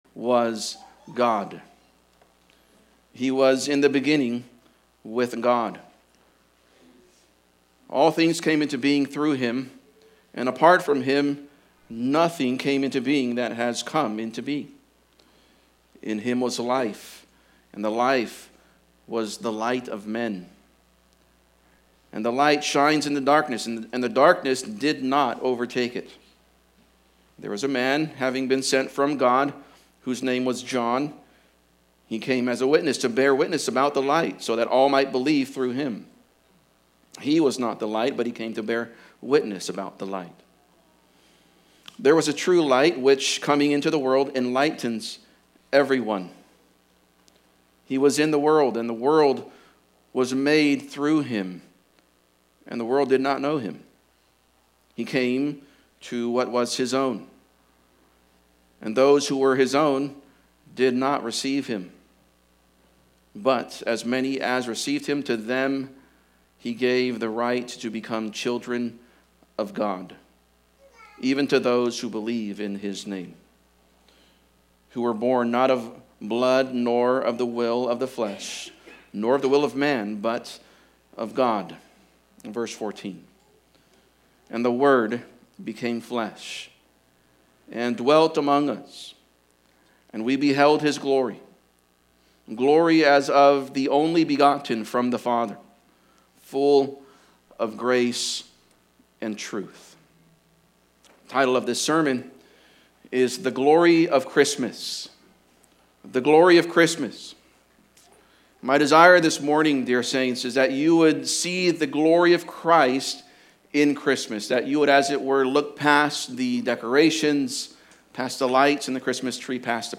Christmas Service 2024 | The Glory of Christmas | Redeemer Bible Church